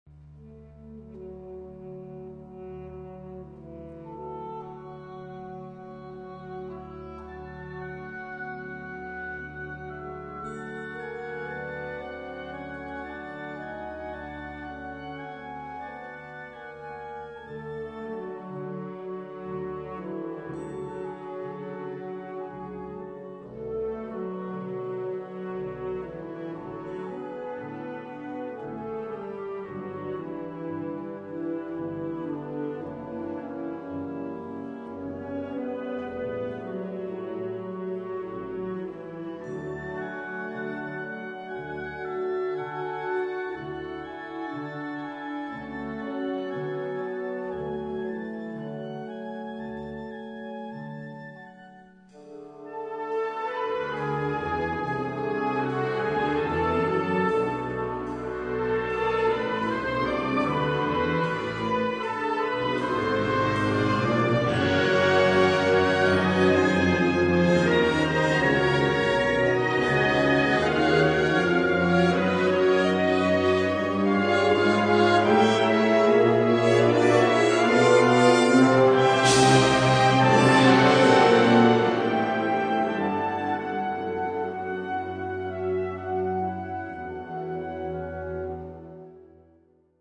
Kategorie Blasorchester/HaFaBra
Unterkategorie Zeitgenössische Originalmusik (20./21.Jhdt)
Besetzung Ha (Blasorchester)